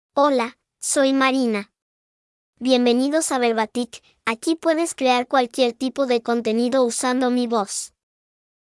FemaleSpanish (Mexico)
Marina — Female Spanish AI voice
Voice sample
Marina delivers clear pronunciation with authentic Mexico Spanish intonation, making your content sound professionally produced.